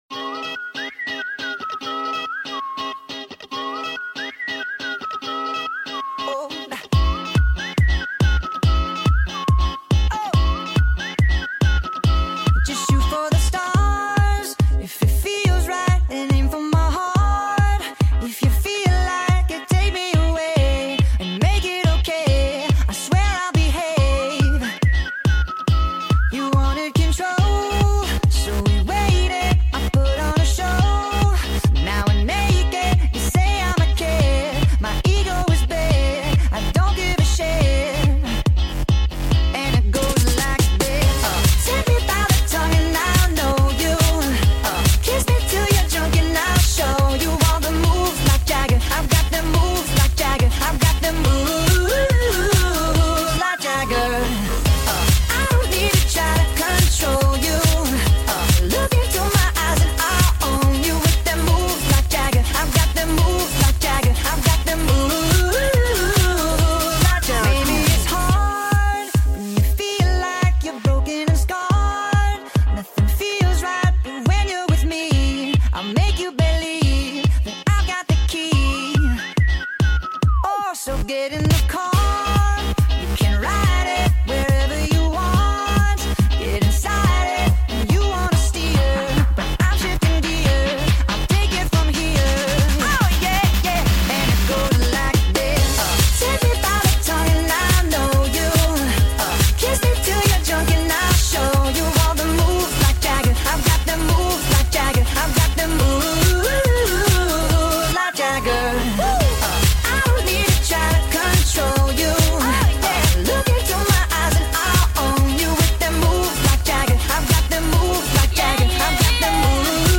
full song sped up